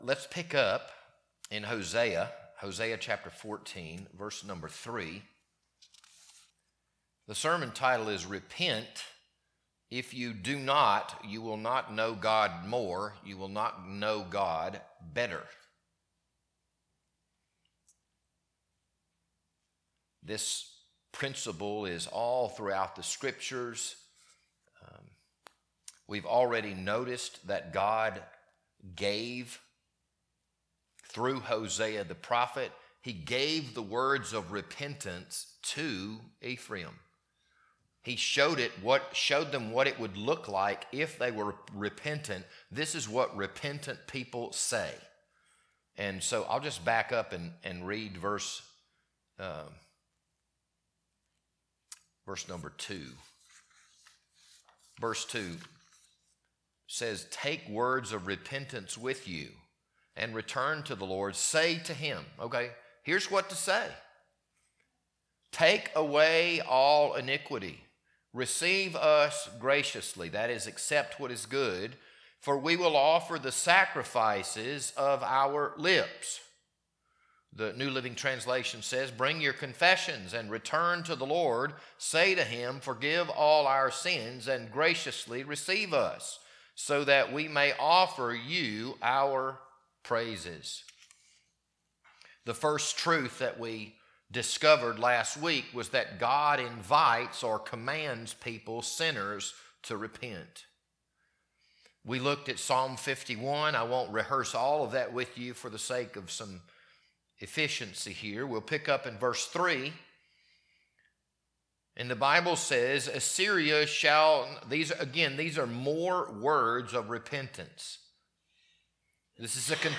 This Sunday evening sermon was recorded on March 22nd, 2026.